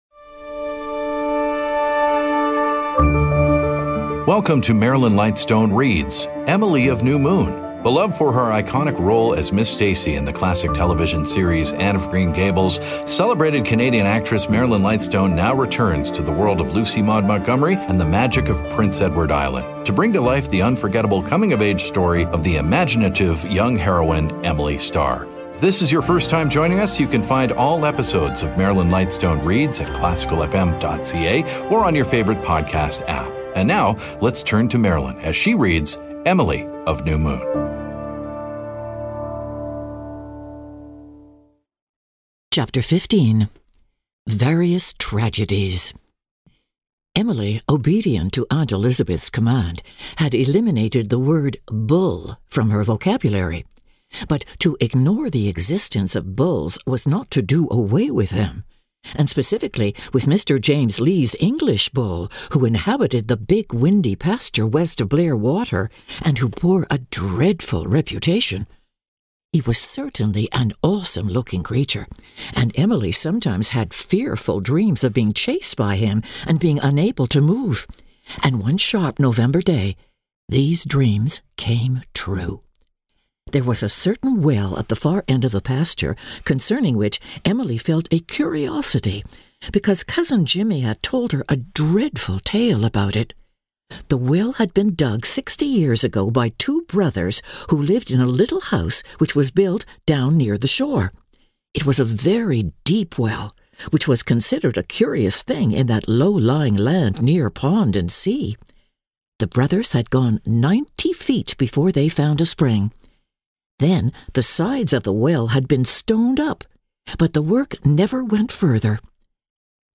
Known for her roles on stage and screen, as well as her daily radio program "Nocturne" on The New Classical FM, acclaimed actress Marilyn Lightstone now brings classic literature to life with dramatic readings.